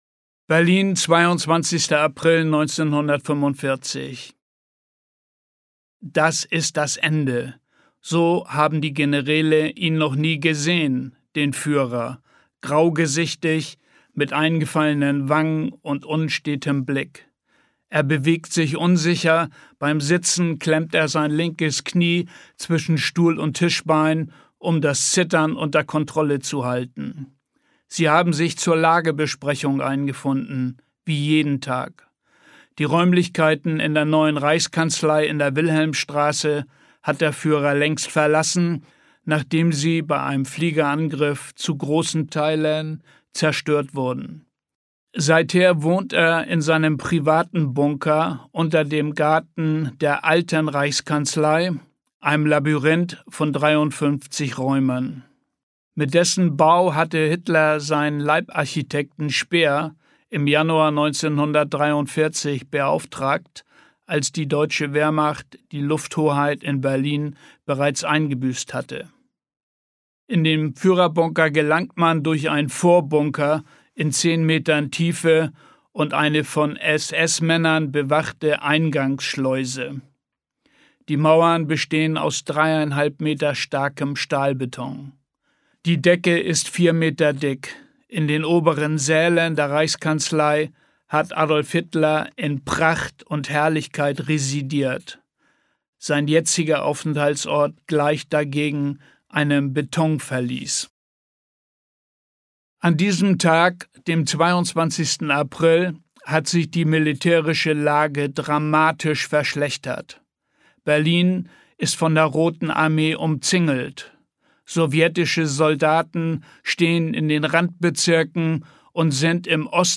Goebbels' Schatten hoerbuch